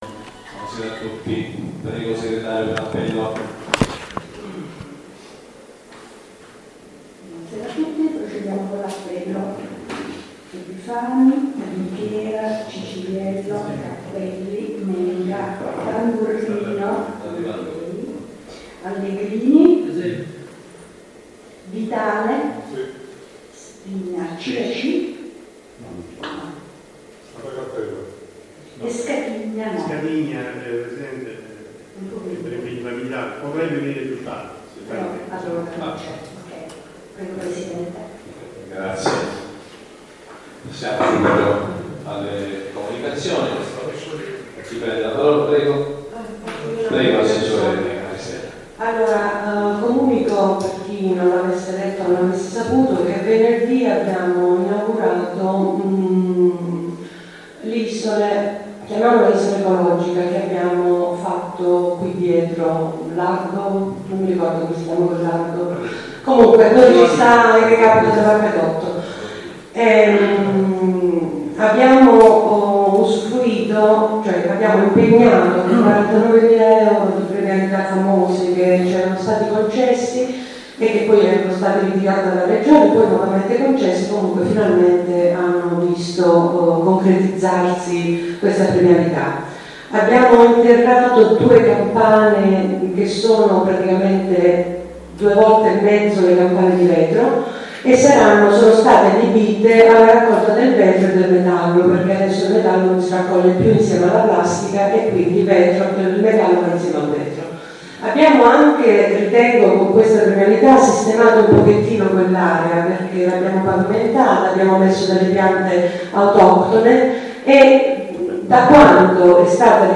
La registrazione audio del Consiglio Comunale di San Michele Salentino del 22/11/2016